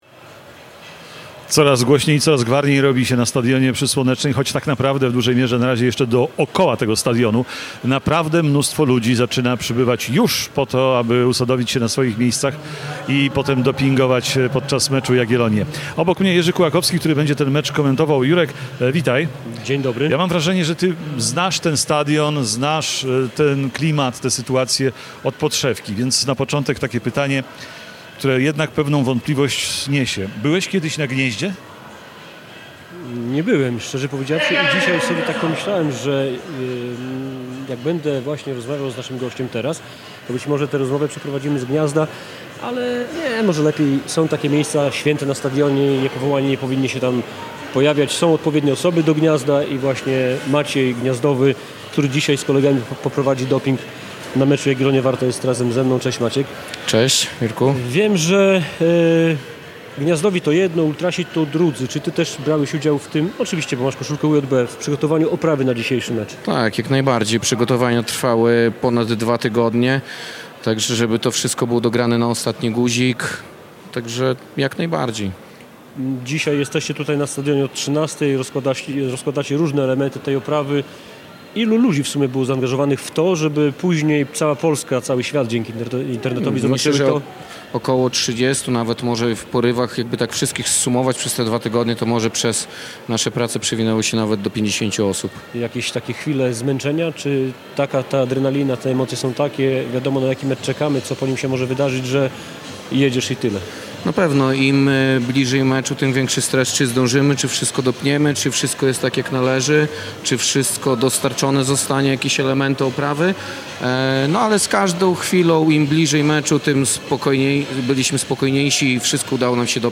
Nasz program rozpoczęliśmy o 14:00 z plenerowego studia na stadionie miejskim w Białymstoku.